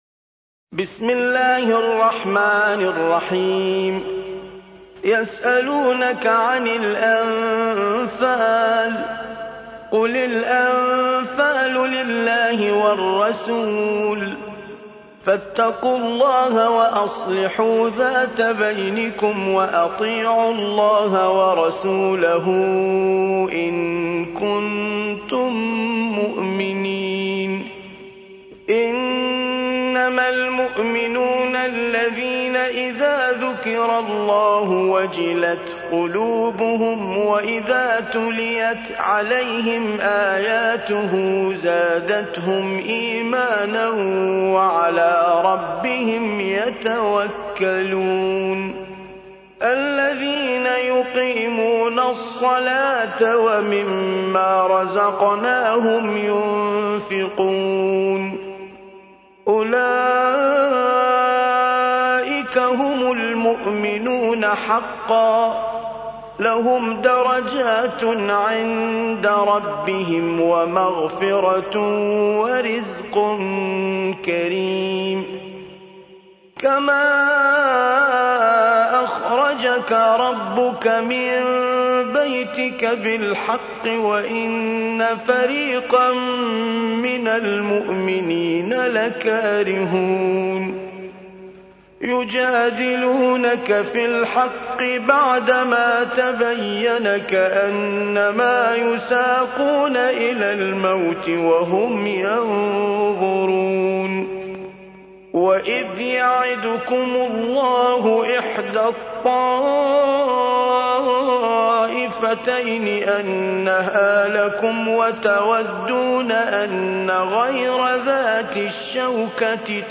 8. سورة الأنفال / القارئ